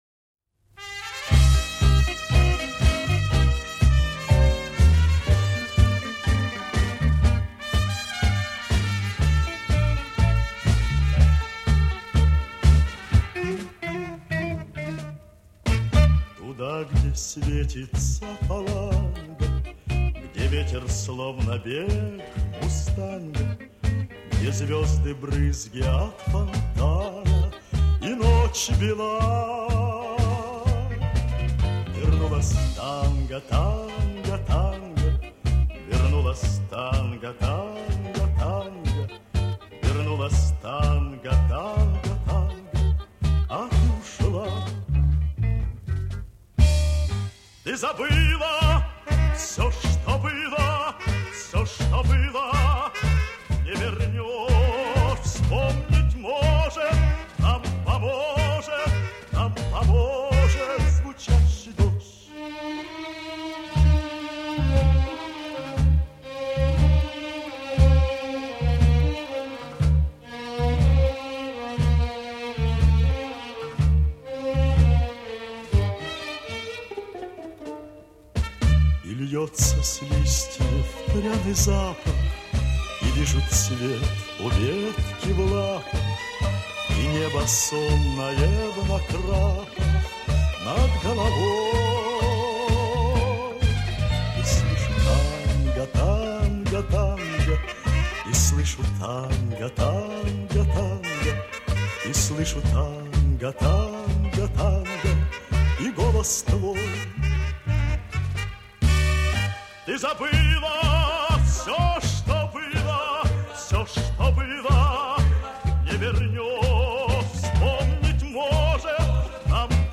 Вок.-инстр. анс.